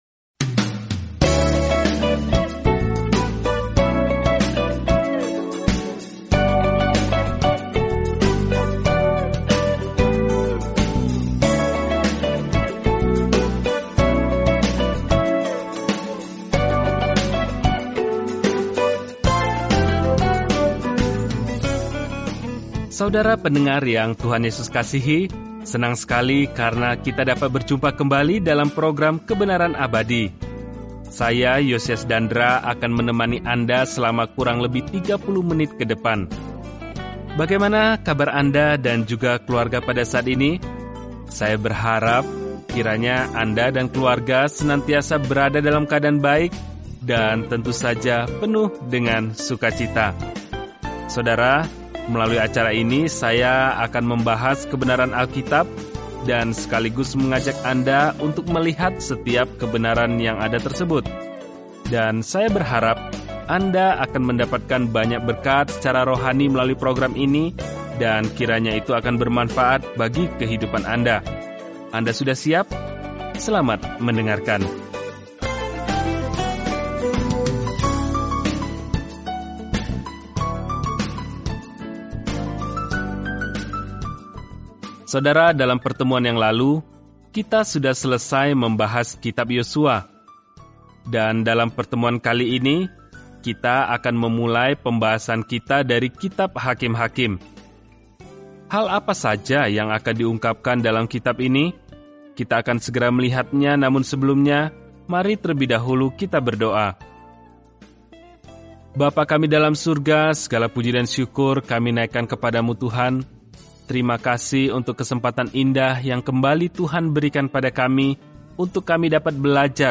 Firman Tuhan, Alkitab Hakim-hakim 1 Hakim-hakim 2 Hakim-hakim 3:1-11 Mulai Rencana ini Hari 2 Tentang Rencana ini Hakim-hakim mencatat kehidupan orang-orang yang terkadang berbelit-belit dan terbalik saat menjalani kehidupan baru di Israel. Perjalanan harian melalui Hakim-hakim saat Anda mendengarkan studi audio dan membaca ayat-ayat tertentu dari firman Tuhan.